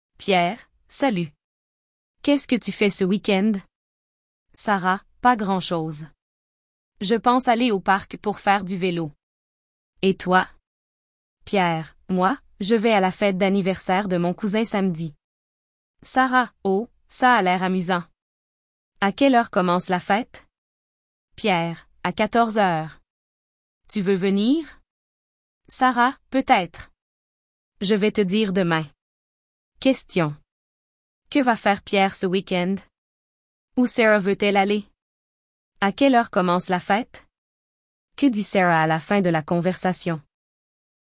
Task 1: Short Dialogues
Two friends discussing weekend plans.